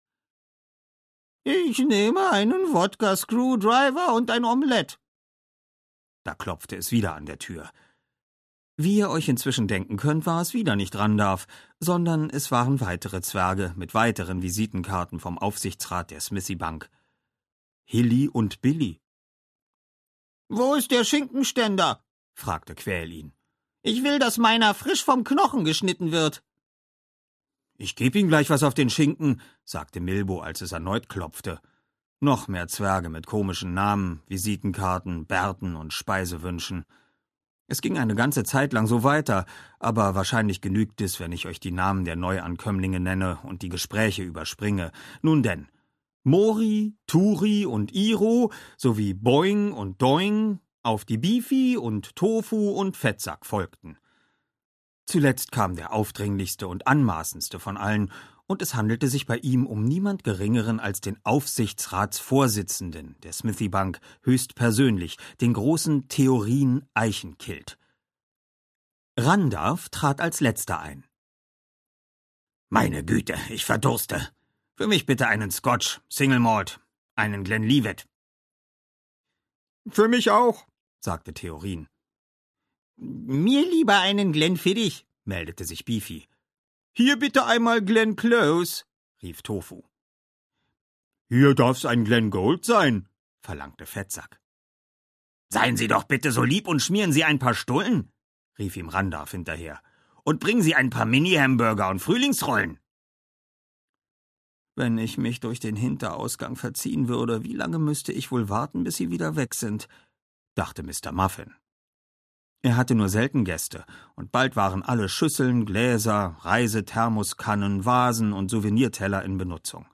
Der Wobbit - Paul Erickson - Hörbuch